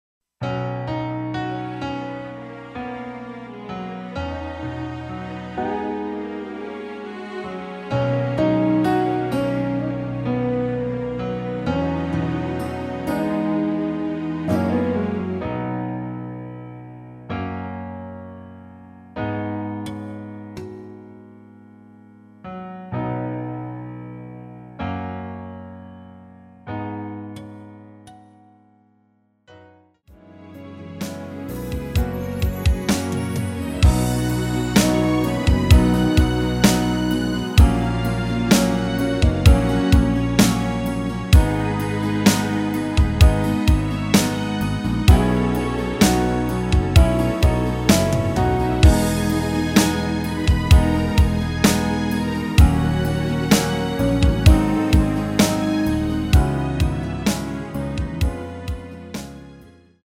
음정은 반음정씩 변하게 되며 노래방도 마찬가지로 반음정씩 변하게 됩니다.
앞부분30초, 뒷부분30초씩 편집해서 올려 드리고 있습니다.
중간에 음이 끈어지고 다시 나오는 이유는
위처럼 미리듣기를 만들어서 그렇습니다.